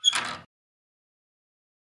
Musket Fire
The sound of Musket Fire at a civil war reenactment.